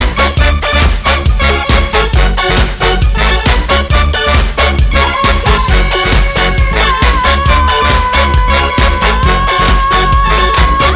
громкие
женский голос
инструментальные
русский рок
с женским вокалом.